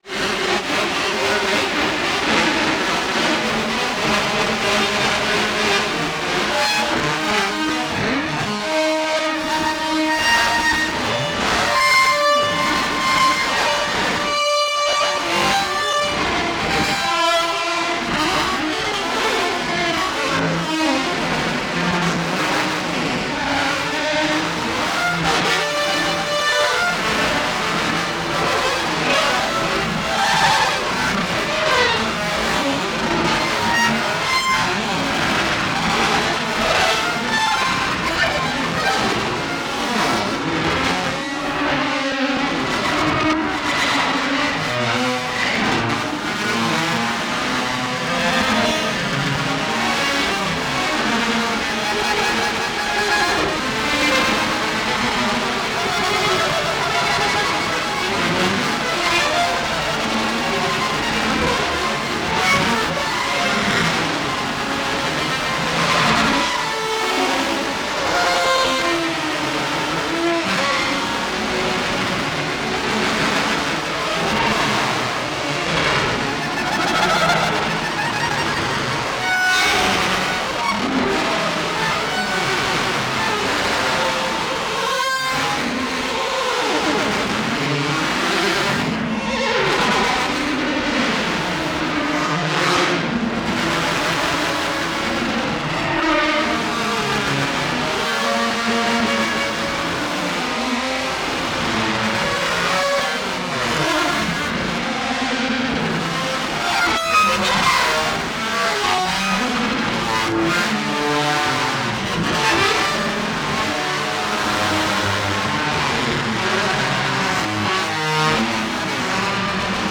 その艶かしい動きは、有機的に変動していく律動。
有機的で奔放な生命力溢れる即興の印象を与えます。
エロティックな機械の軋み、或はメカニカルな生命の息吹、その真逆のものが同一時空に美しいカオスを作っているような。
現代音楽、先端的テクノ、実験音楽をお好きな方々にはもちろん、ギター愛好家の方々にもお薦めのアルバムです。